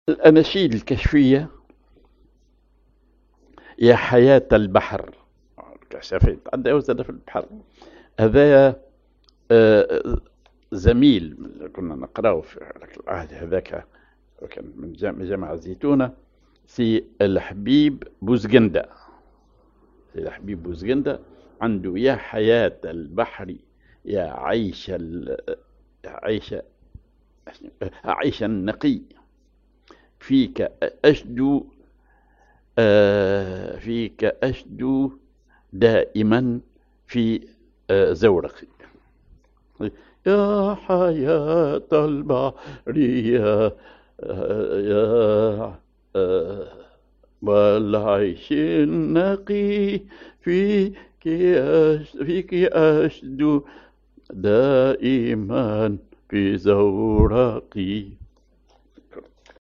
Maqam ar كردي
Rhythm ar فالس
genre نشيد